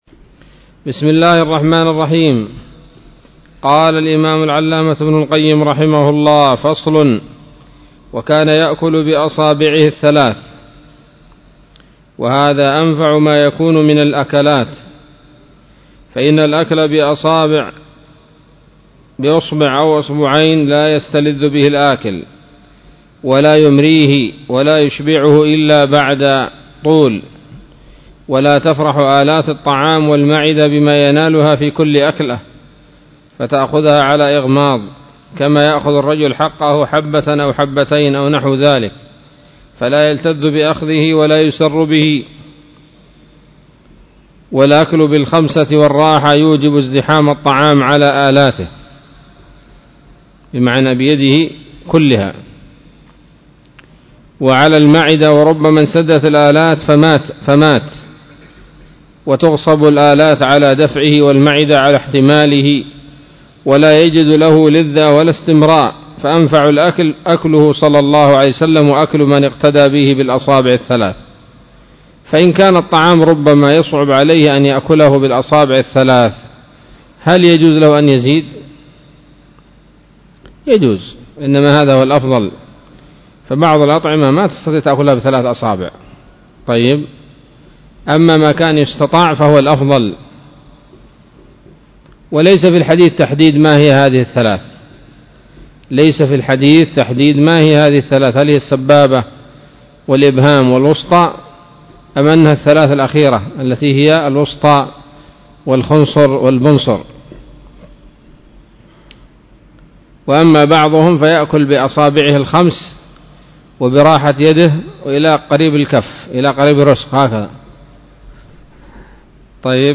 الدرس الحادي والستون من كتاب الطب النبوي لابن القيم